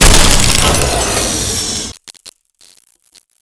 Index of /cstrike/sound/turret